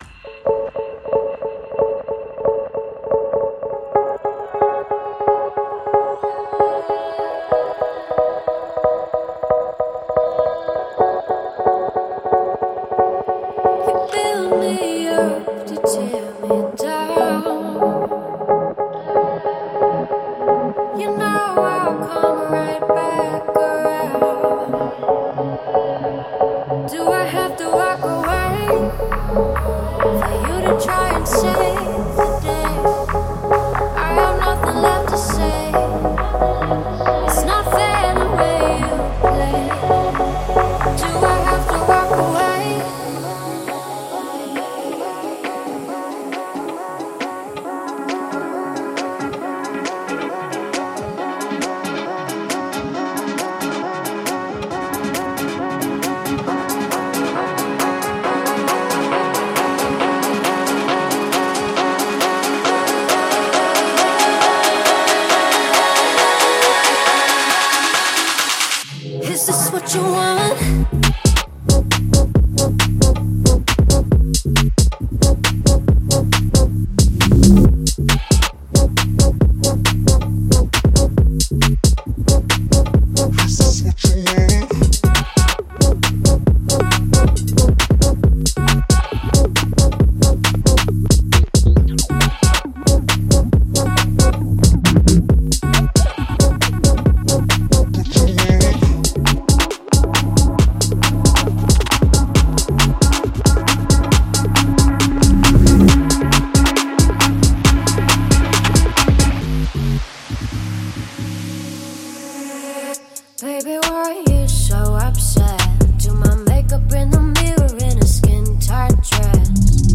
# Electro